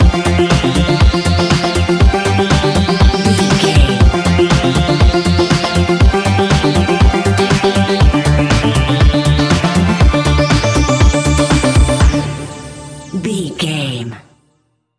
Ionian/Major
D
house
electro dance
synths
techno
trance